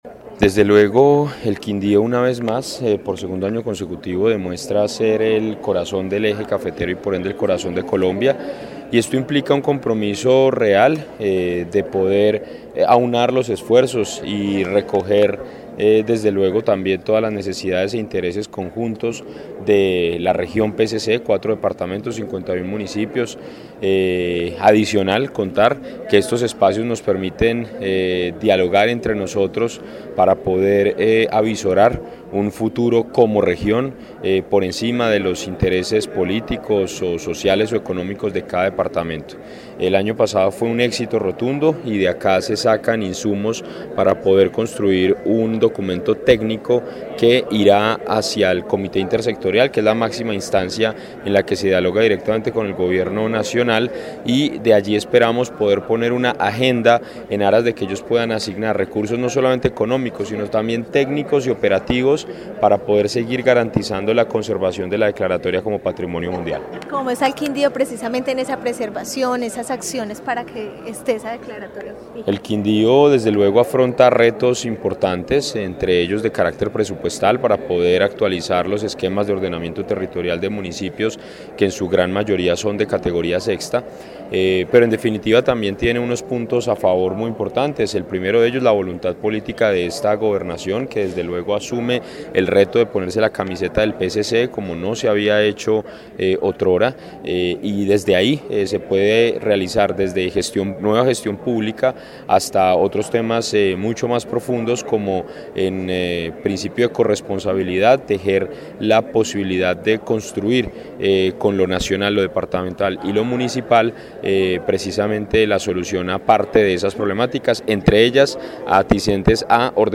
Secretario de Cultura del Quindío